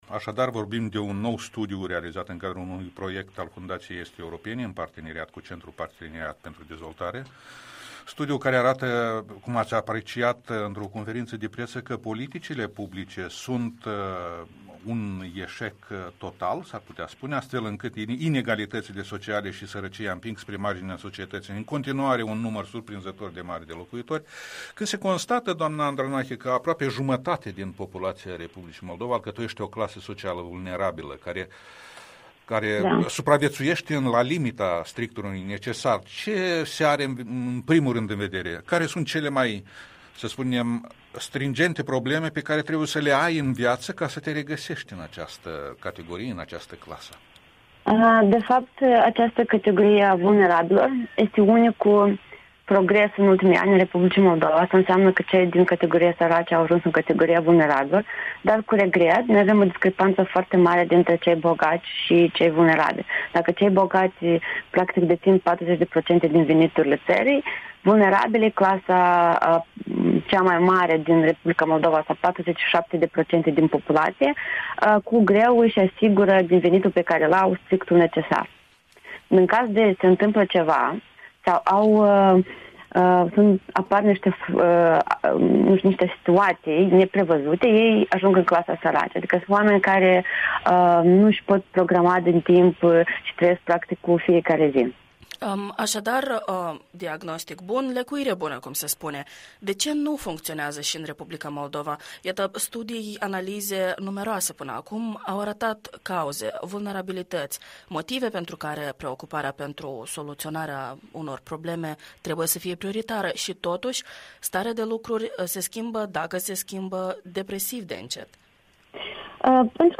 Interviul matinal la Radio Europa Liberă